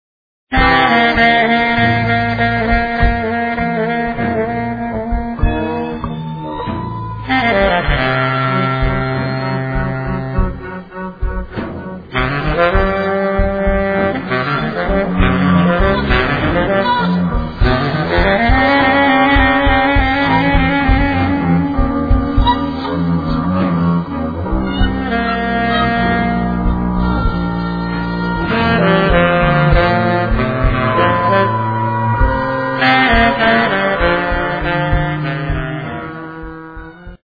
freejazz
baritone saxophone
el. doublebass
drums
saw, el. guitar
piano). Live from Prague [2002].